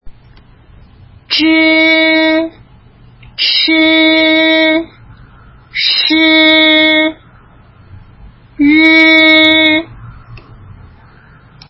そり舌音
zh(i) (無気音)舌先をそり上げ、上あごにあてて、弱い息で｢ジー｣と発音するイメージ。
ch(i) (有気音)舌先をそり上げ、上あごにあてて、強い息で｢チー｣と発音するイメージ。
sh(i) 舌先をそり上げ、上あごに近づけ、息で舌先を擦るように｢シー｣と発音するイメージ。
r(i) 舌先をそり上げ、上あごに近づけ、喉で声をだし発音するイメージ。